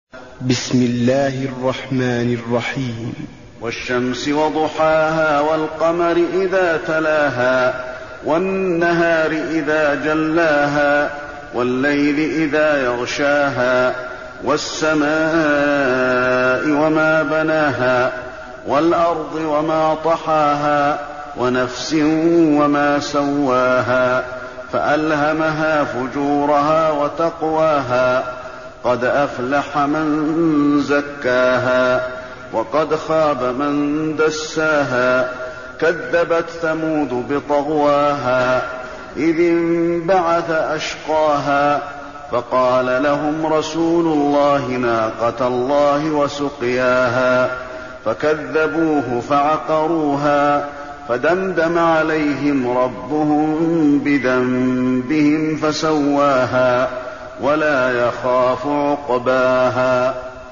المكان: المسجد النبوي الشمس